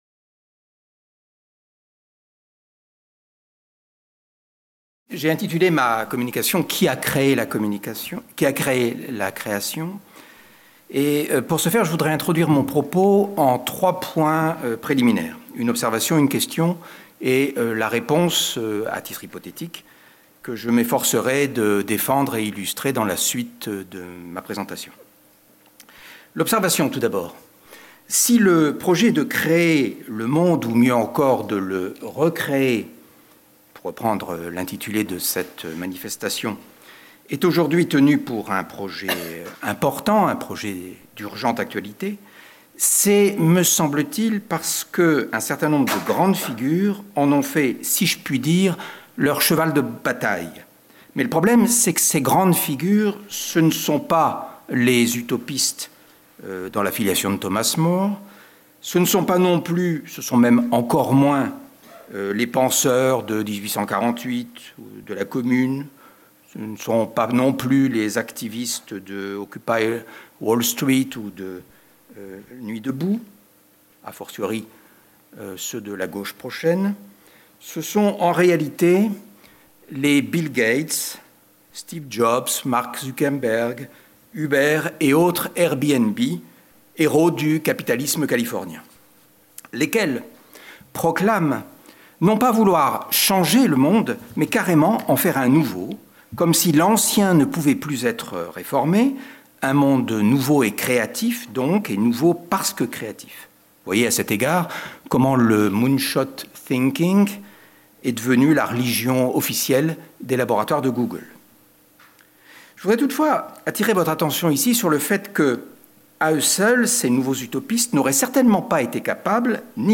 Jeudi 19 mai - Philharmonie, salle de conférence 9h30 Création : origines, sens et mythologie : qui a créé la création